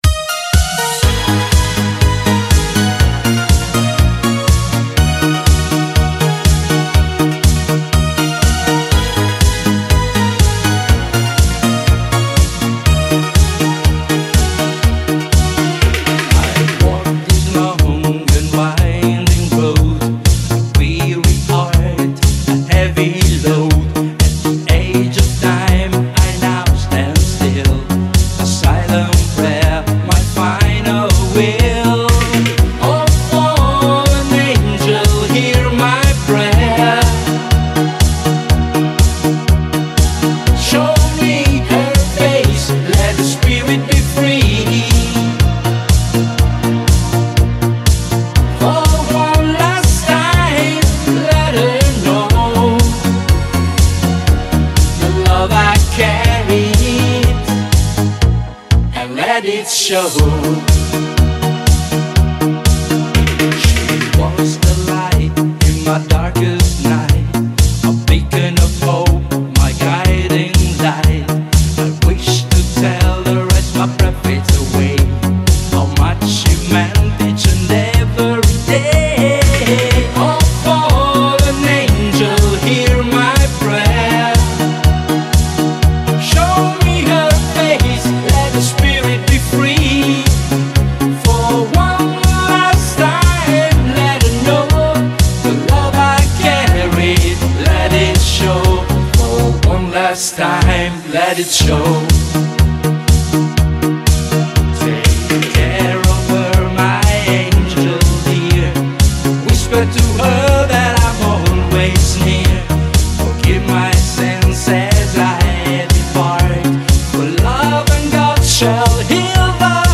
An uptempo energetic Italo Disco track